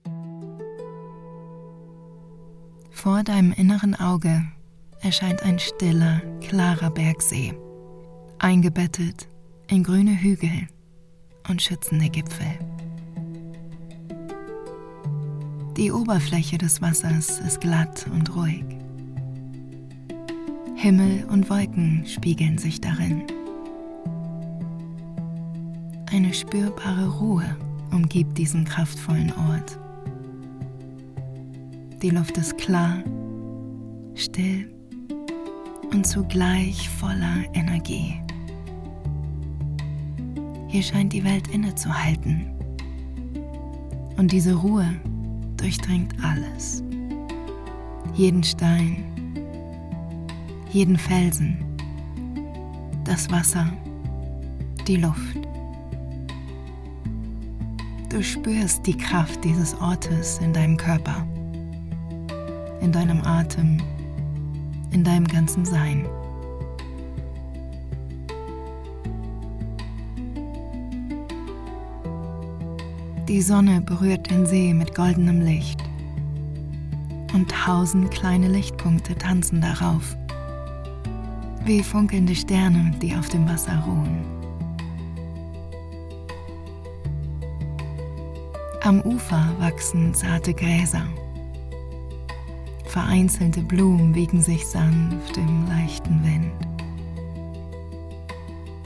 Kurzeinblick Audioreise 1
Drei exklusive Audioreisen Professionell eingesprochen, mit Musik hinterlegt und inspiriert von Mentaltraining (Dauer zw. 15 und 25 Minuten).